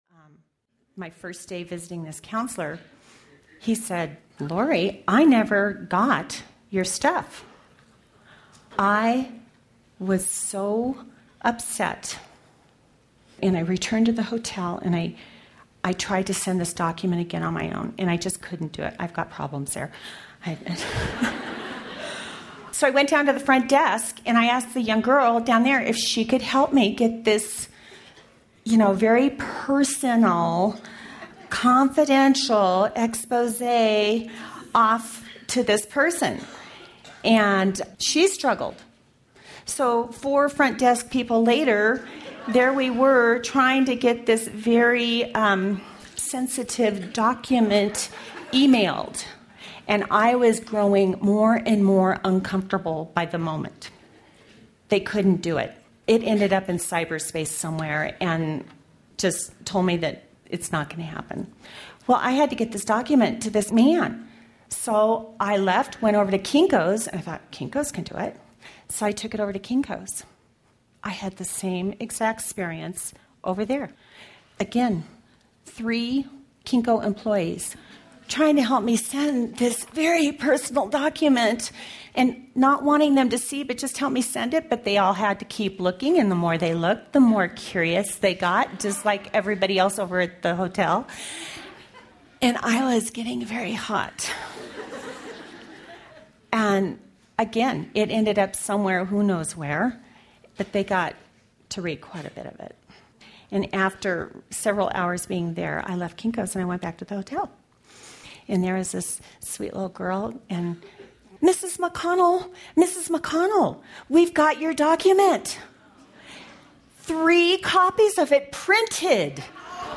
Fallen Eve Audiobook
1.25 Hrs. – Unabridged